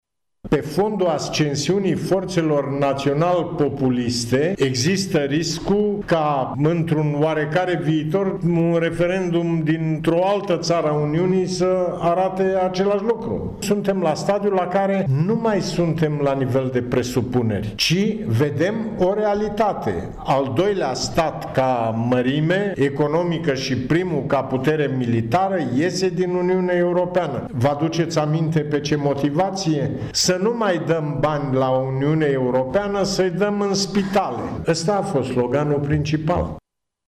Prezent astăzi la Universitatea de Medicină, Farmacie, Științe și Tehnologie din Târgu Mureș fostul președinte al României, Traian Băsescu, a atras atenția că, după BREXIT există și un risc al dispariției Uniunii Europene, dacă se cedează în fața valului populist:
Traian Băsescu a vorbit studenților deaspre „România și perspectivele Uniunii Europene”, într-o conferință ce face parte din seria întâlnirilor „Președinți la UMFST”.